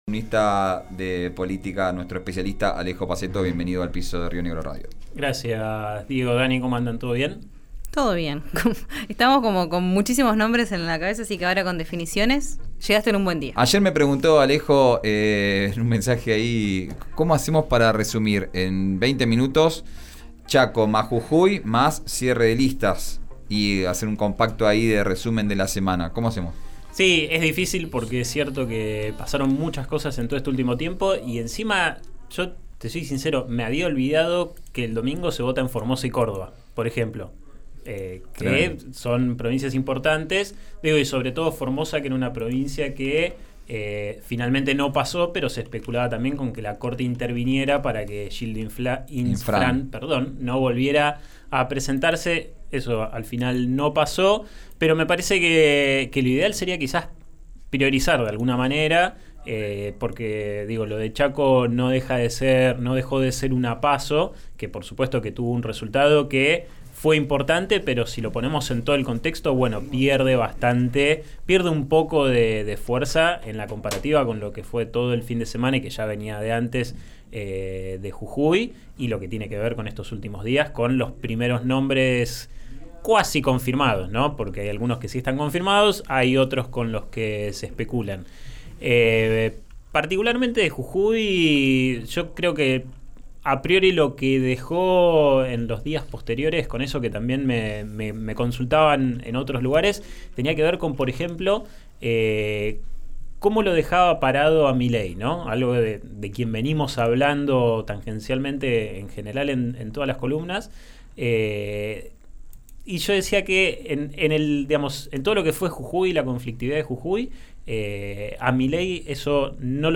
Análisis político: De Jujuy al cierre de listas - Diario Río Negro